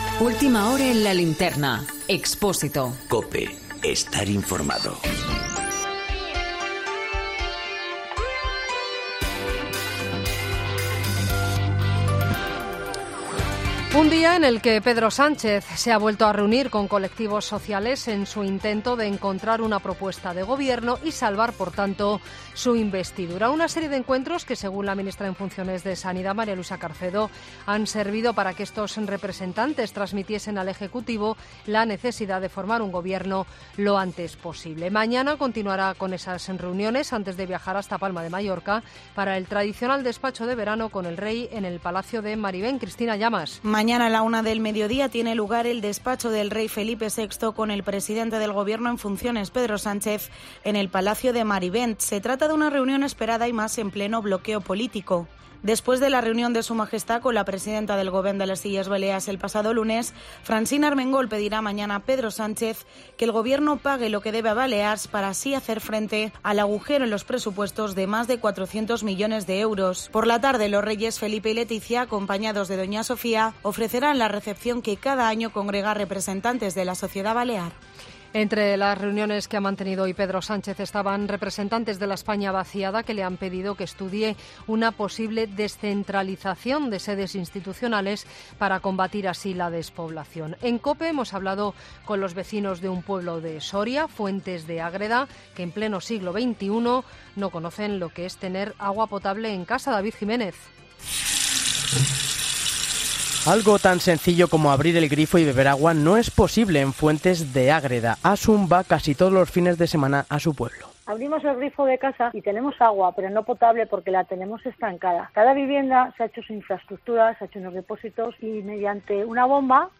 Boletín de noticias Cope del 6 de agosto a las 23.00 horas